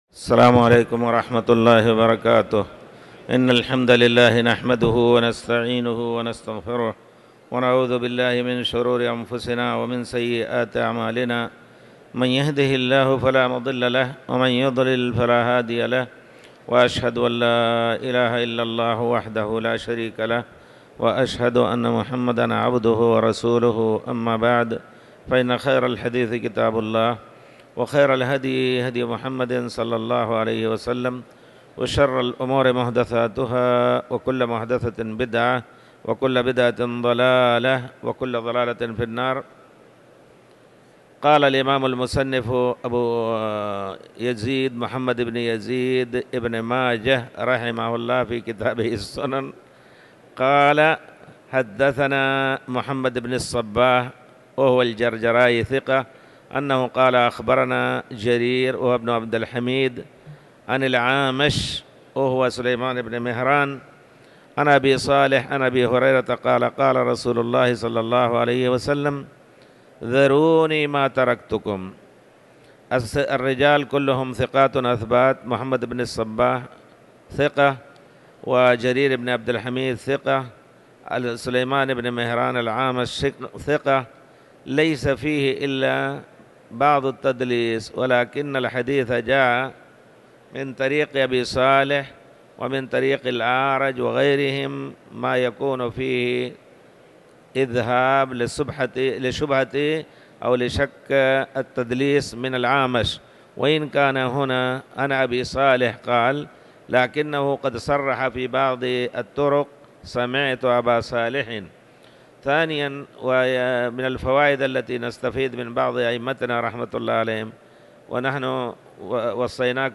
تاريخ النشر ٤ رمضان ١٤٤٠ هـ المكان: المسجد الحرام الشيخ